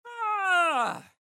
Yawn 1
People Sound Effects
Yawn_1-1-sample.mp3